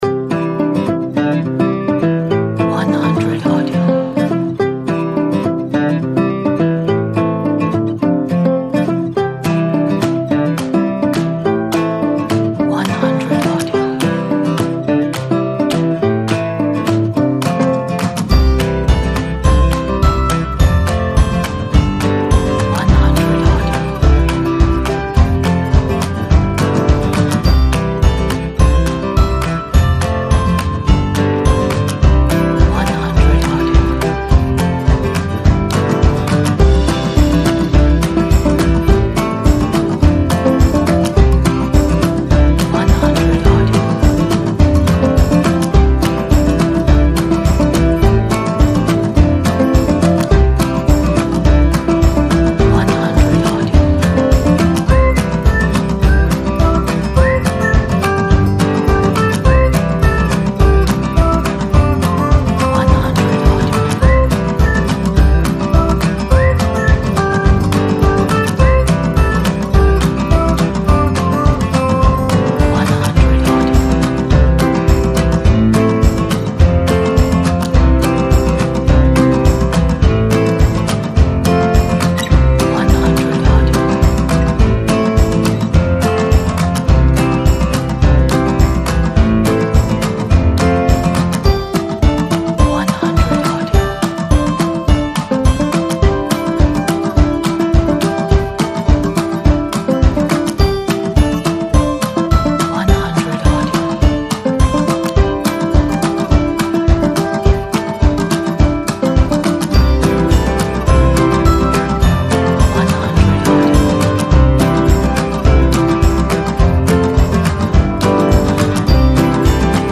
快乐 振奋 不插电 广告